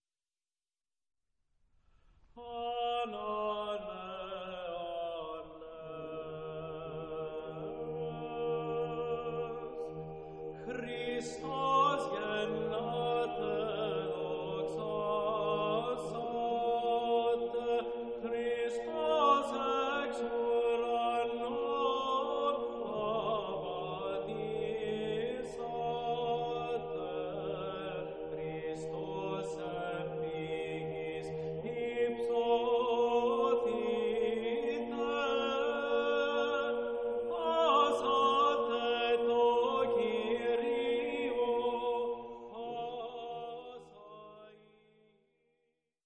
Christmas Music from the Byzantine Tradition